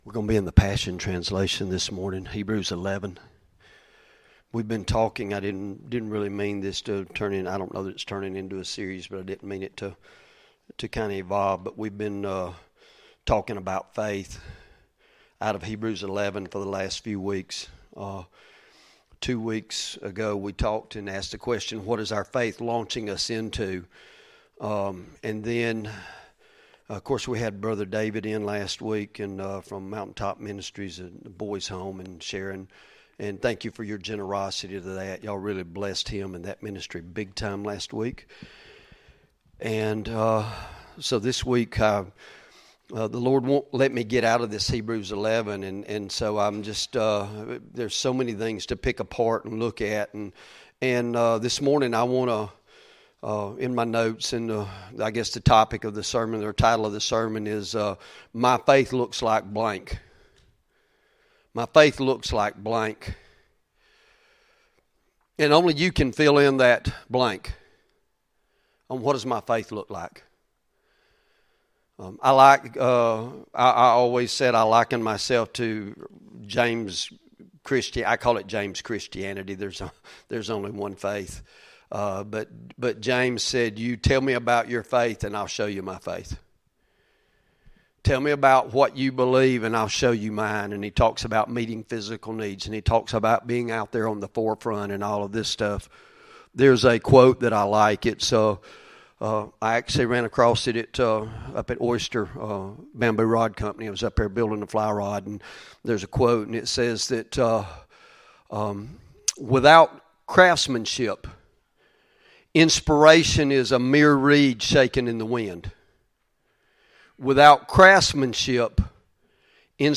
Sermon Audio Downloads | Victory Fellowship